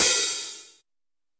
H-HAT OP 900.WAV